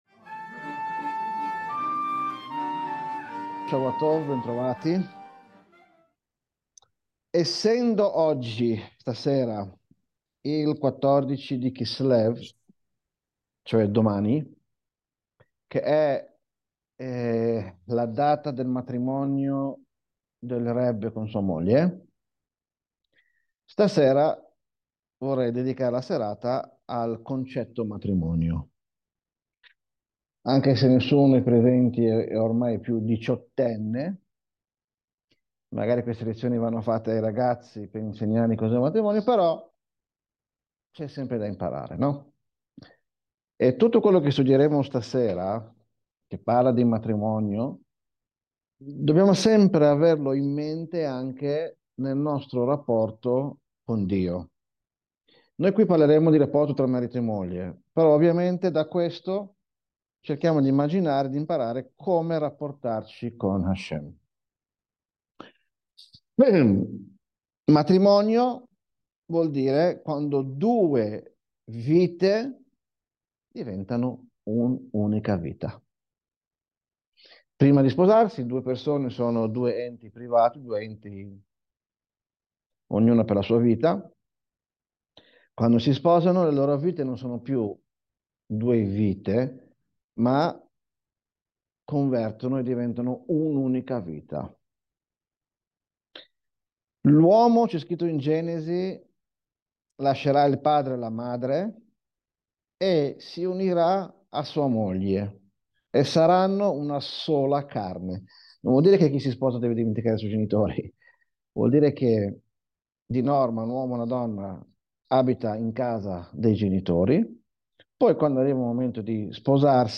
Lezione del 14 dicembre 2024